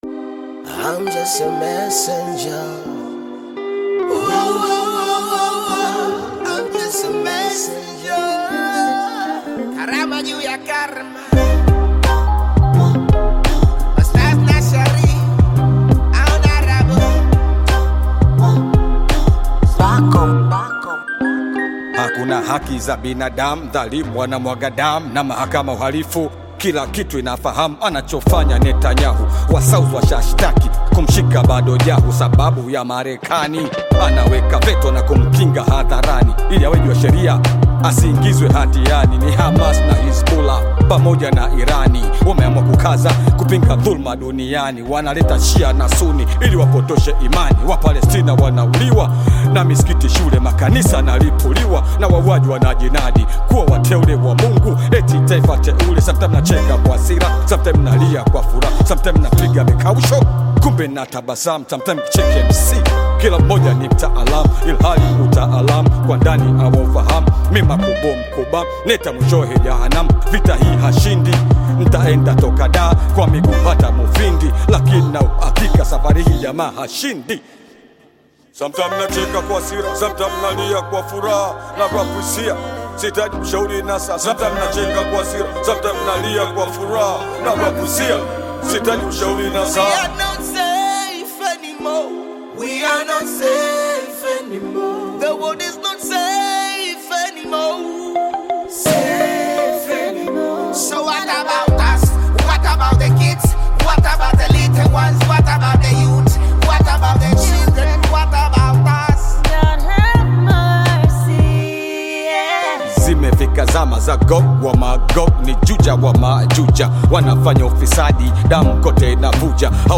Bongo Flava You may also like